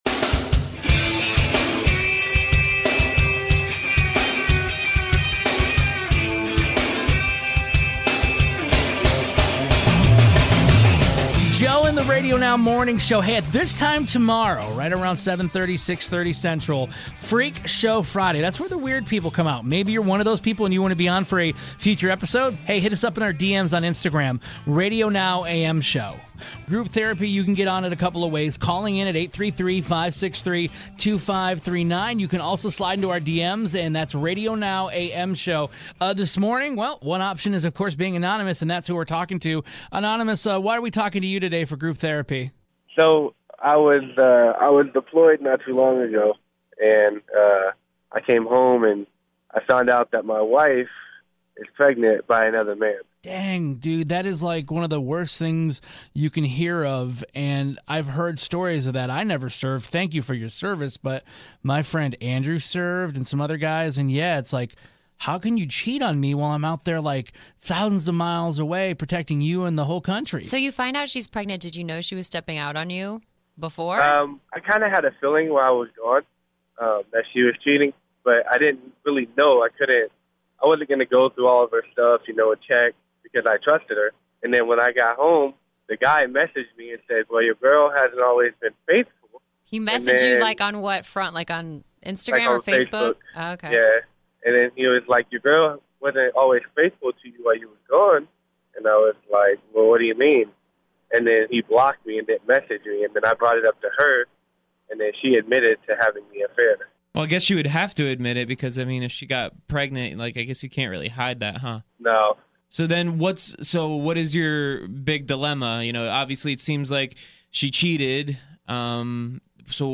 A listener who wanted to be "anonymous" called in because he was trying to figure out what he should do since he has come home from deployment.